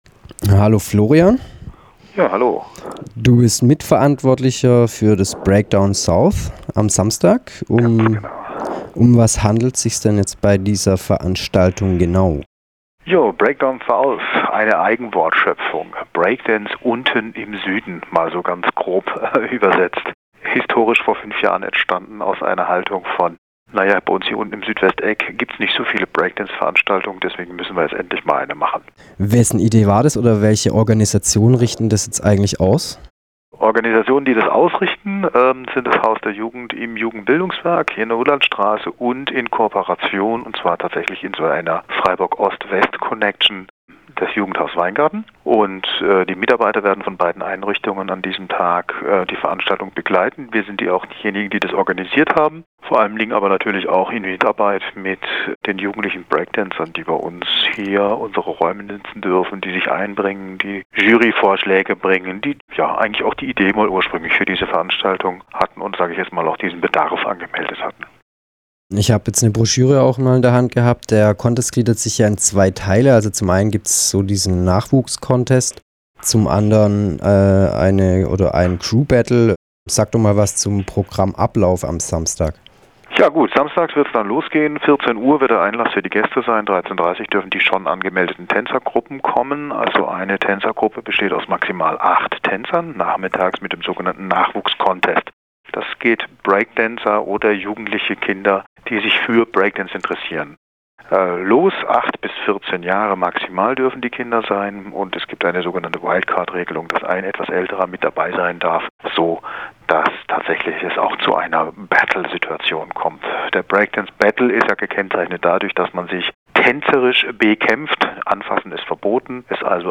Flashmob gegen schwarz-gelbe Atomverlängerung Studio-Interview